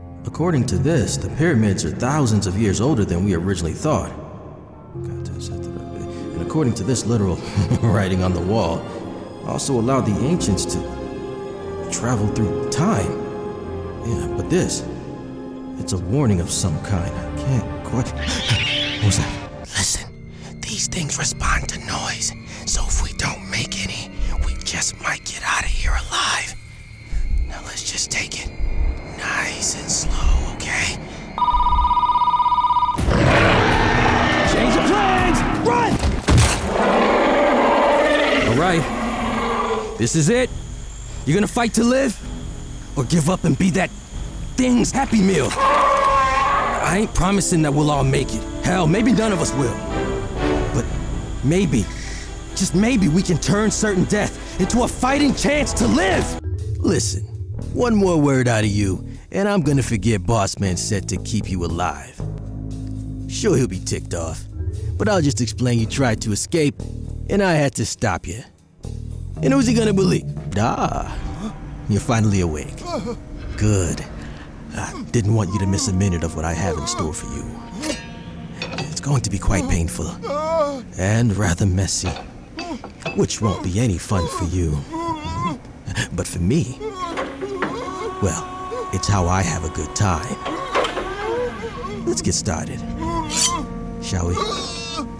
Videogame Demo - Grounded, Genuine, Scientist, Soldier, Regular Guy, Evil, Henchman
East Coast, General American
Young Adult
Middle Aged
If you’re looking for a grounded, believable male voice for your project, look no further!